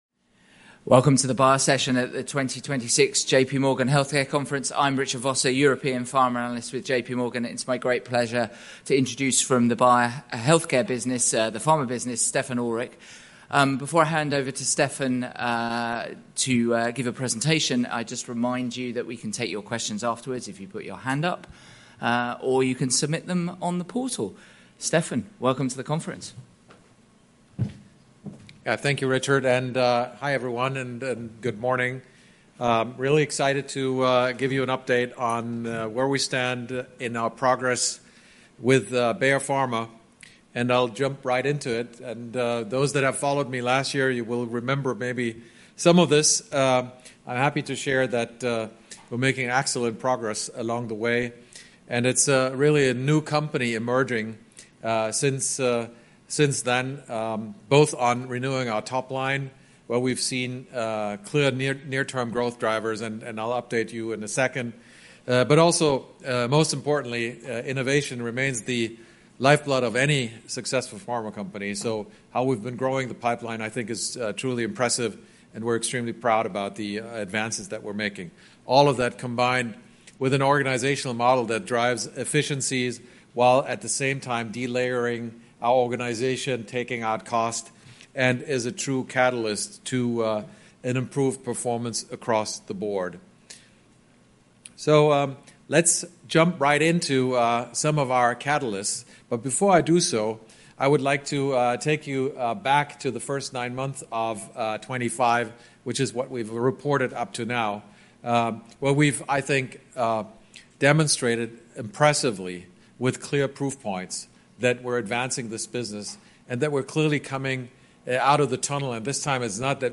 recording-jp-morgan-healthcare-conference-san-francisco-2026.mp3